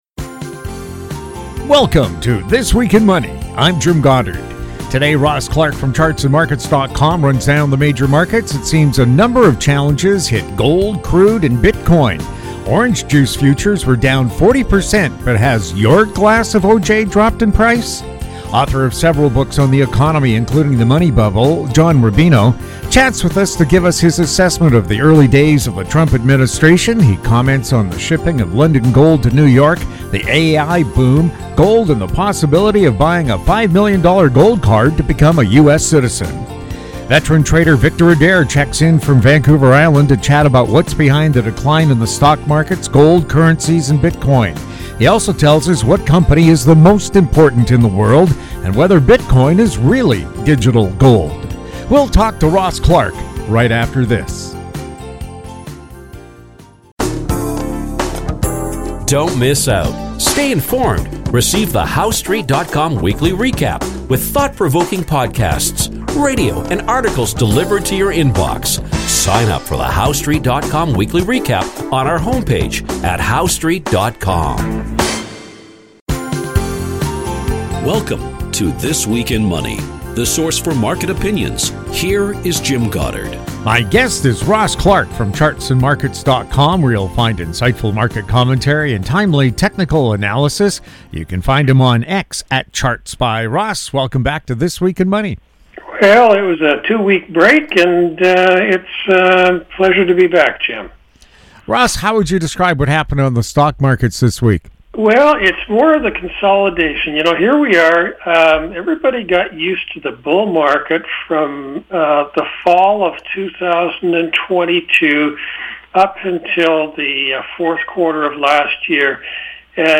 March 1, 2025 | This Week in Money This Week in Money Visit Show Archives This Week in Money presents leading financial news and market commentary from interesting, informative and profound guests. They are some of the financial world's most colorful and controversial thinkers, discussing the markets, economies and more!
New shows air Saturdays on Internet Radio.